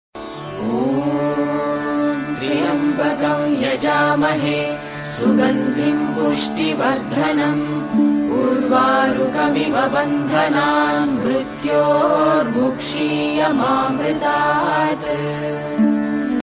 CategoryDevotional Ringtones